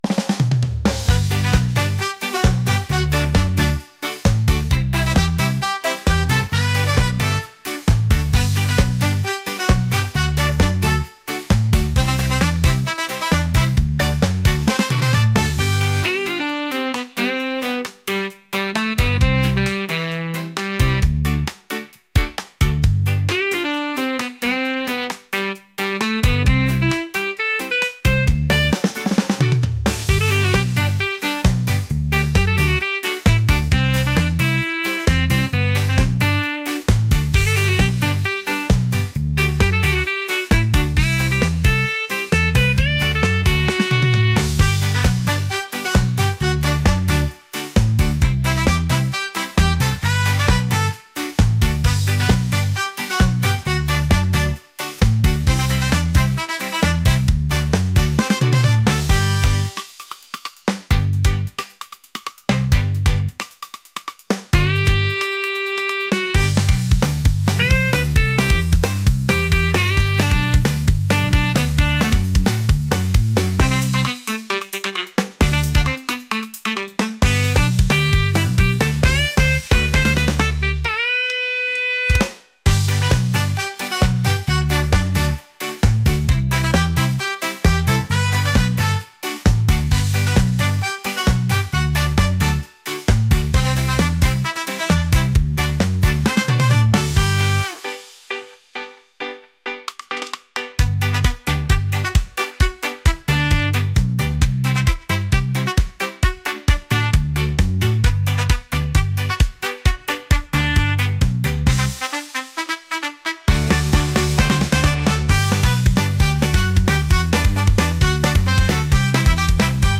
upbeat | reggae | ska | energetic